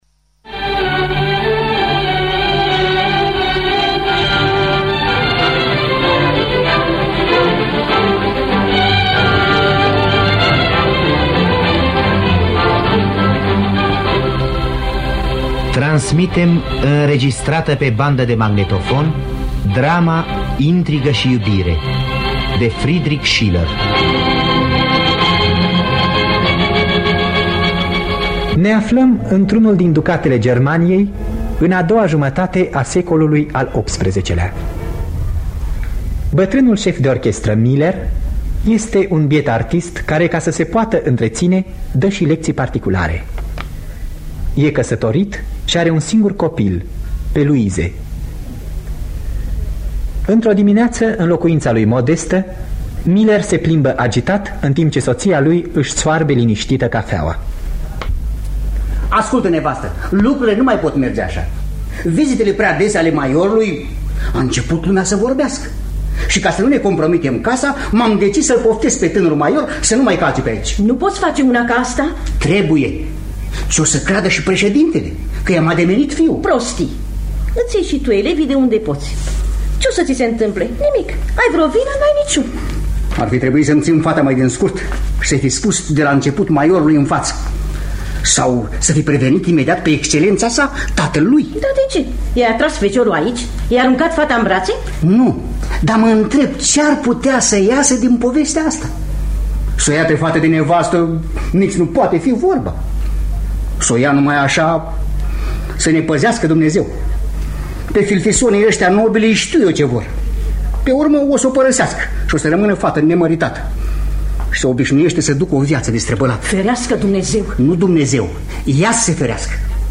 Intrigă şi iubire de Friedrich Schiller – Teatru Radiofonic Online